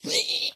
zpighurt2.ogg